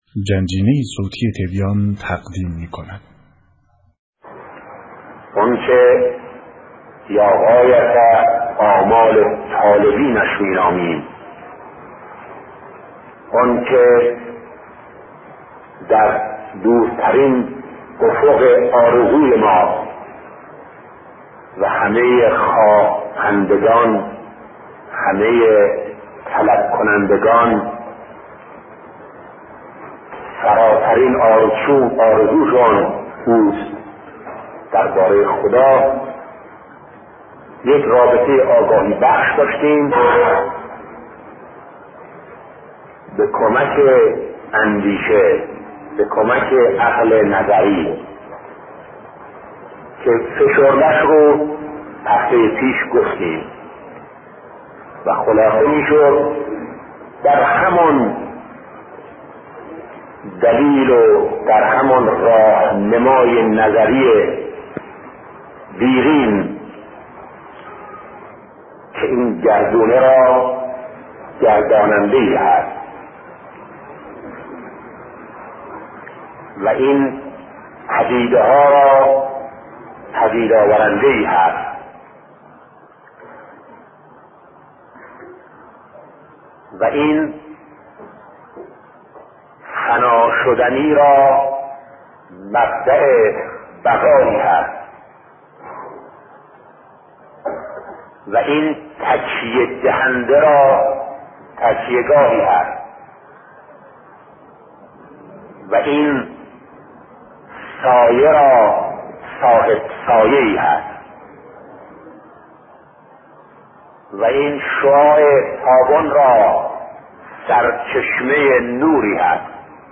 سخنرانی شهید بهشتی(ره)- راه میان بر به سوی حق